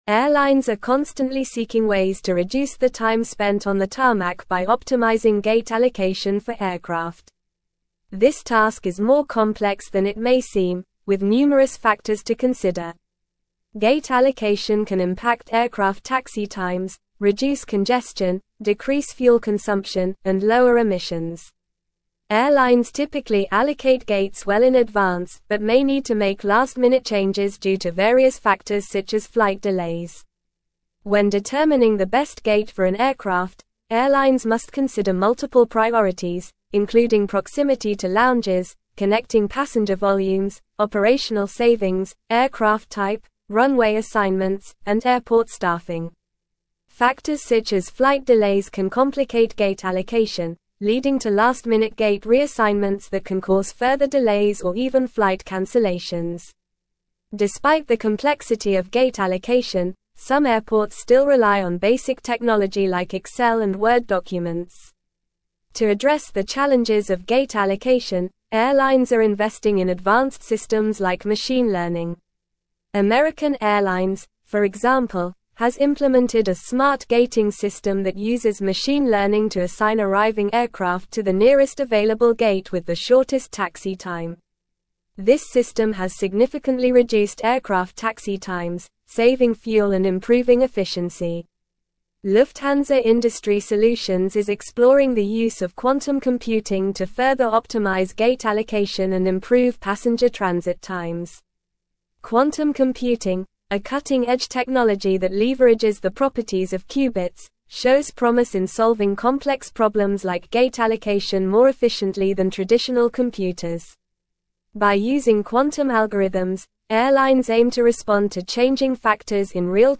Normal
English-Newsroom-Advanced-NORMAL-Reading-Efficient-Gate-Allocation-in-Airports-Innovations-and-Benefits.mp3